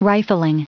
Prononciation du mot rifling en anglais (fichier audio)
Prononciation du mot : rifling